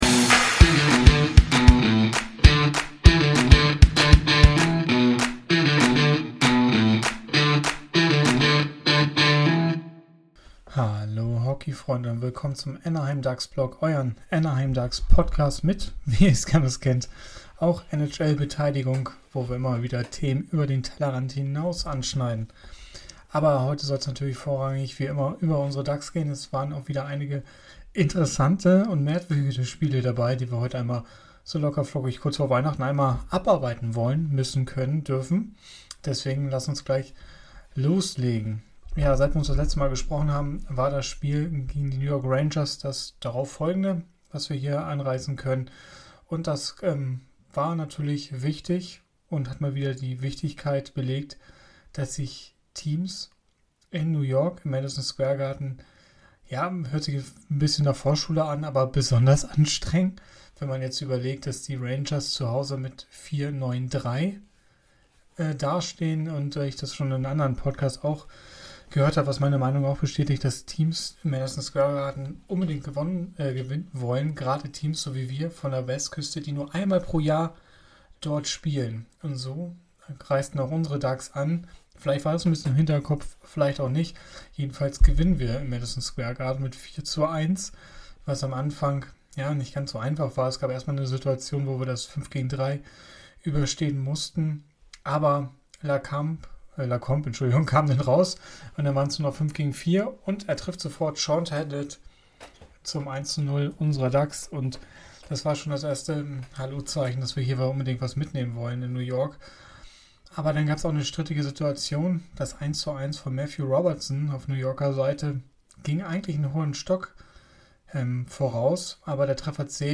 Hallo Hockeyfreunde, mit angeschlagener Stimme besprechen wir heute die jüngsten Spiele der Ducks und analysieren die aktuelle Situation. Dazu schauen wir genau auf die Tabelle und besprechen die Wichtigkeit der nächsten Spiele.